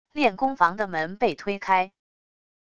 练功房的门被推开wav音频